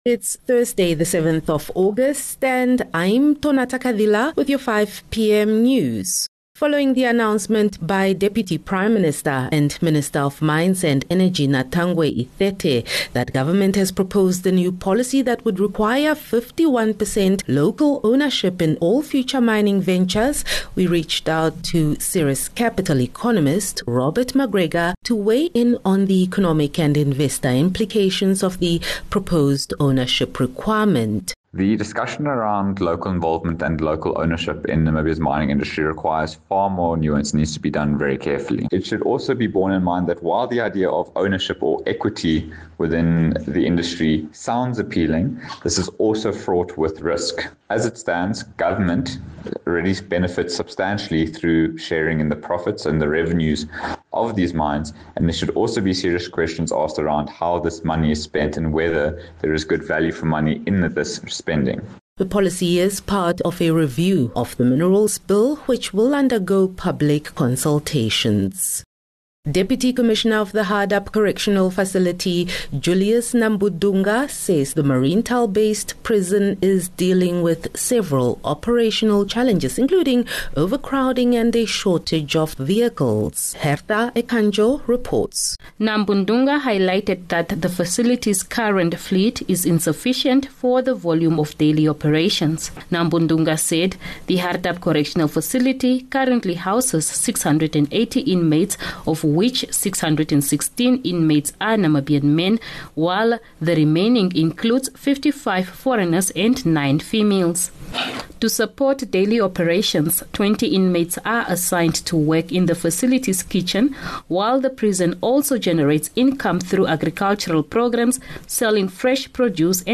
7 Aug 7 August - 5 pm news